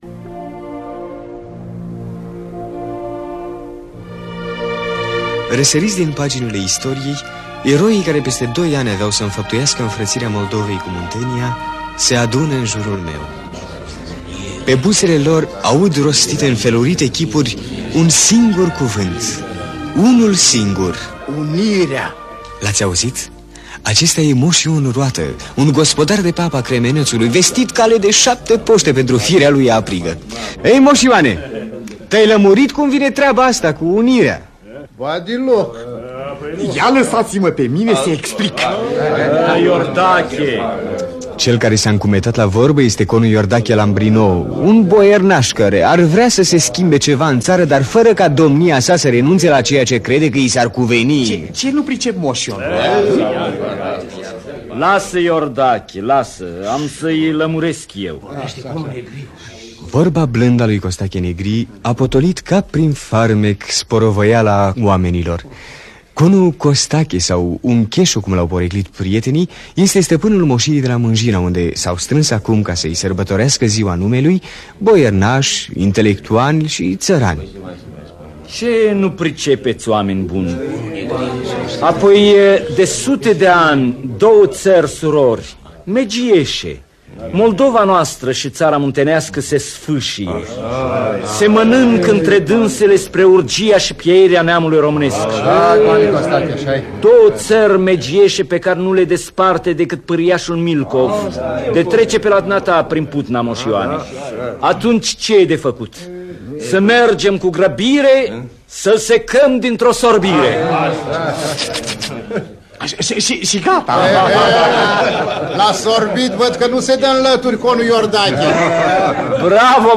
"În zilele unirii". Scenariu radiofonic de Tudor Șoimaru